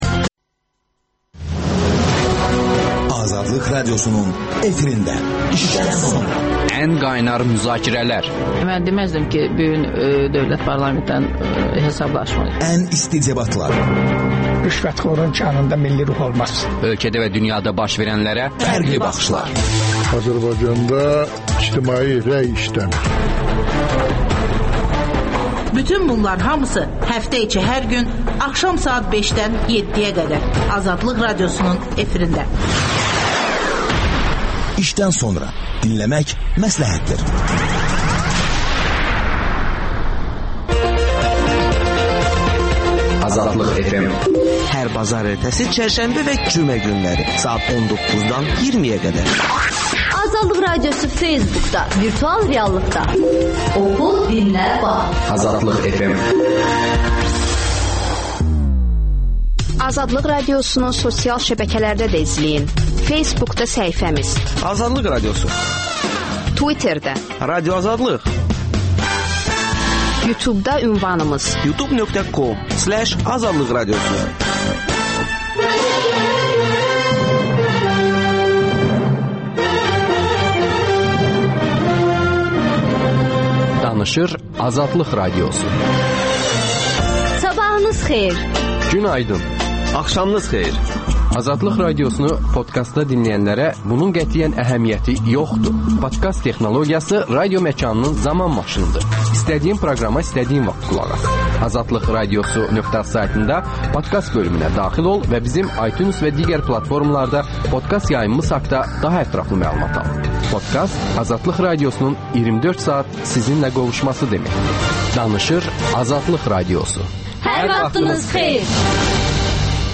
AXCP sədri Əli Kərimli ölkədə baş verən hadisələr və müxalifətin 2013 seçkilərinə hazırlığı barədə suallara cavab verir